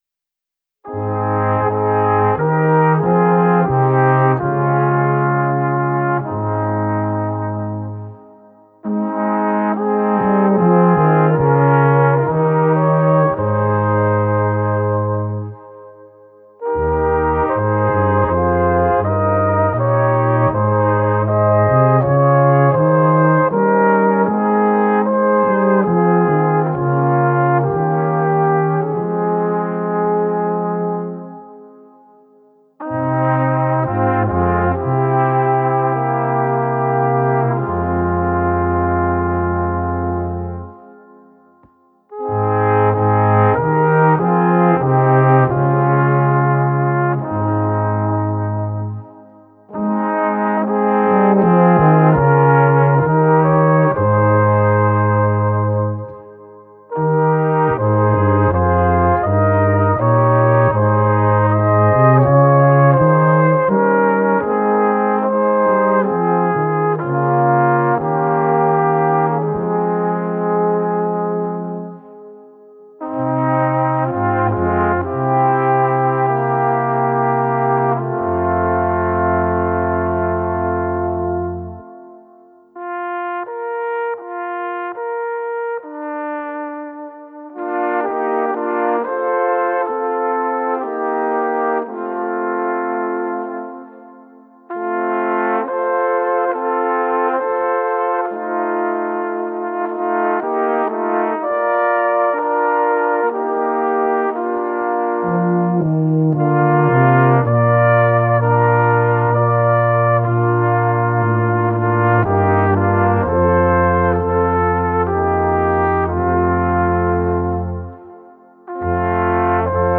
Gattung: Weihnachtliche Weisen für 4 oder 5 Blechbläser